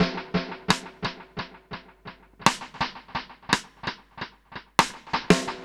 Index of /musicradar/dub-drums-samples/85bpm
Db_DrumsA_SnrEcho_85_03.wav